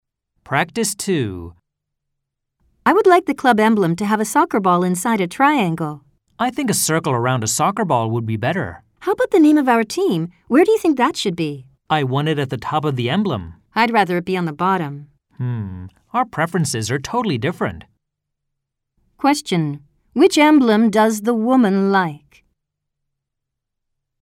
計7名以上（米・英・豪）によるバラエティ豊かな音声を収録。
ハイスピード（1回読み）
UNIT1_Practice2_hispeed.mp3